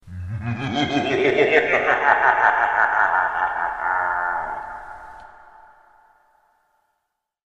creepy-laughing_14157.mp3